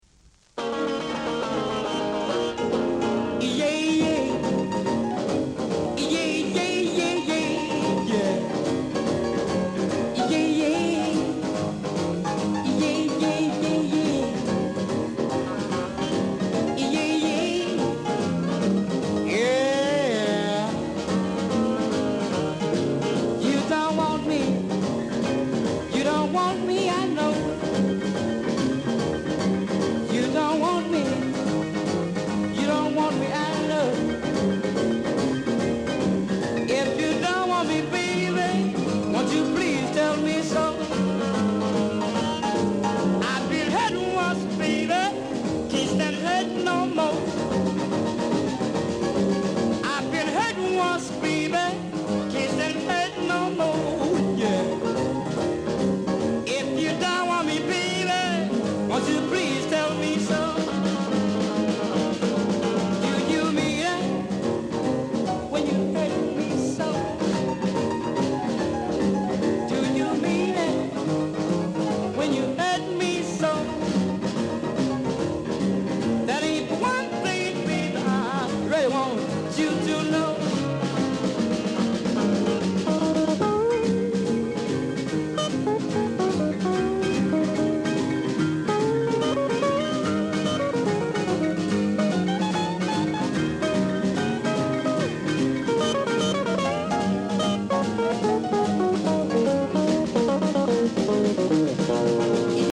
Blues Male Vocal